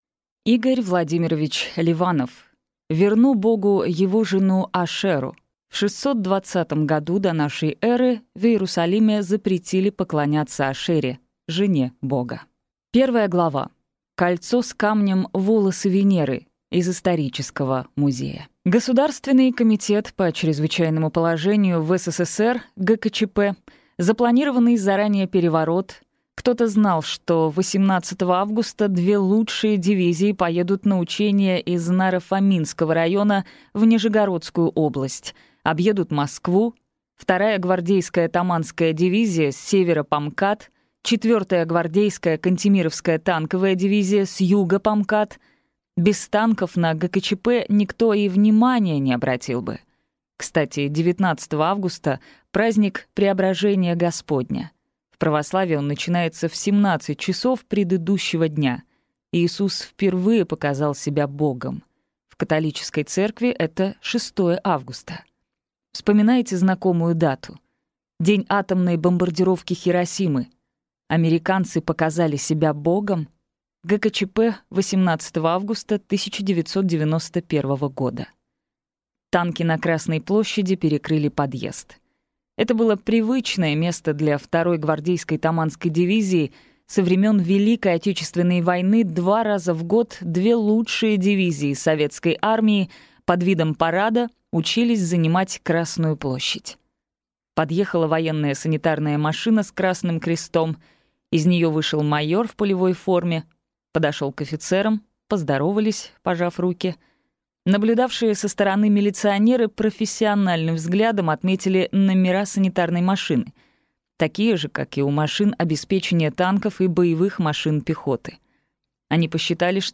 Аудиокнига Верну Богу его жену Ашеру. В 620 году до нашей эры в Иерусалиме запретили поклоняться Ашере – жене Бога | Библиотека аудиокниг